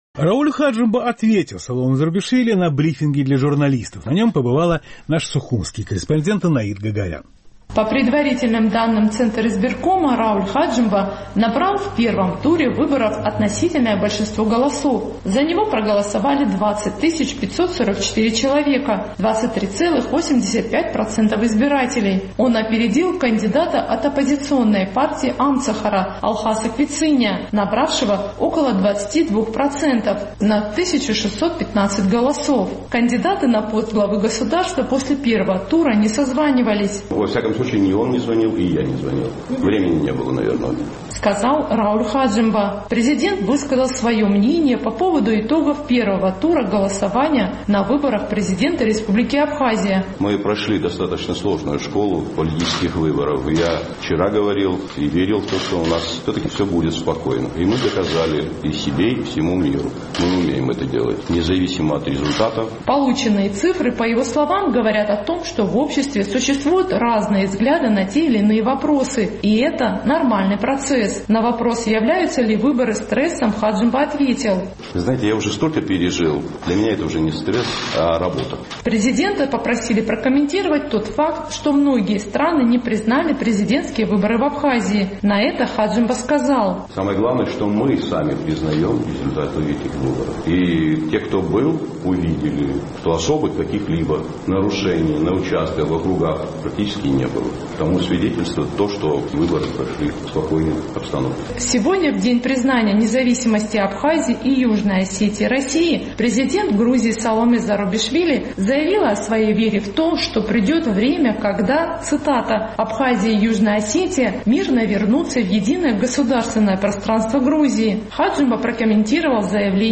Президент Абхазии Рауль Хаджимба провел брифинг для журналистов, на котором заявил, что выборы, в которых он также участвует в качестве кандидата, прошли спокойно и демократично.